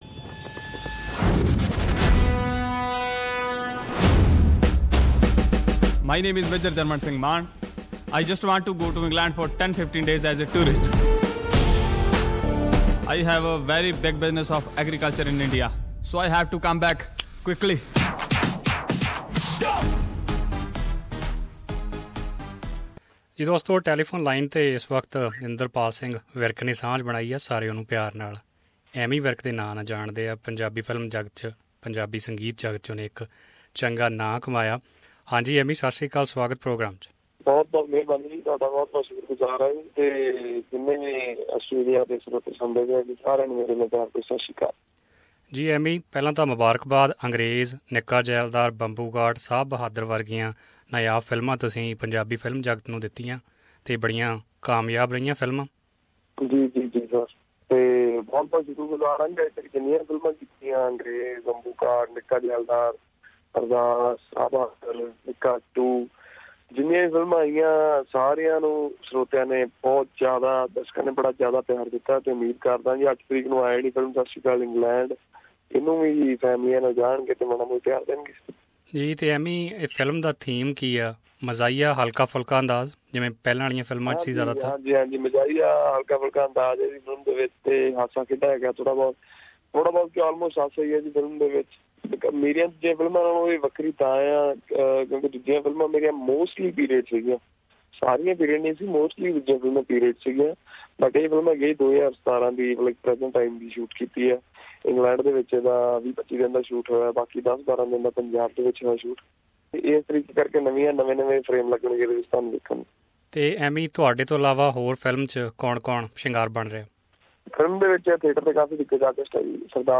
Not just trailer for the film made everyone dig into the movie but also the songs of Sat Shri Akal England with melodious voice, tuning and lyrics are making it count. Here we have a conversation with singer-turned actor Amy Virk who is in the main lead of this movie.